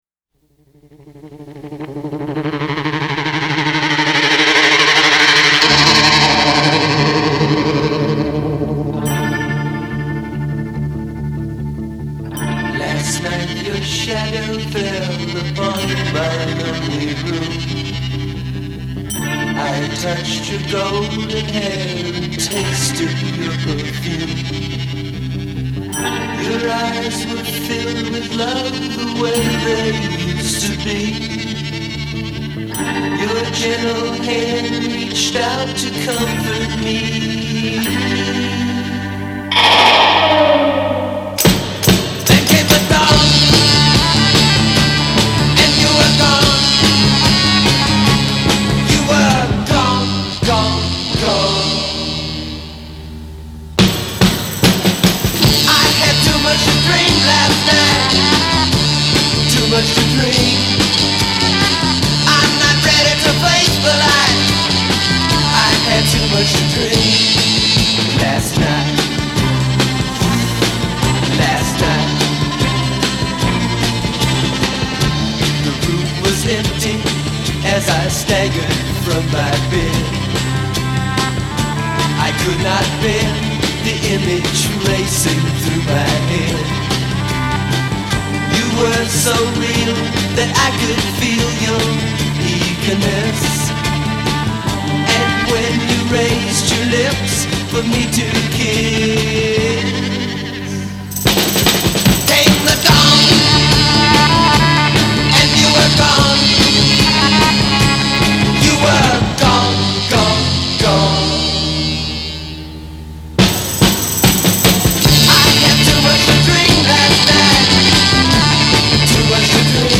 West Coast psychedelic rock band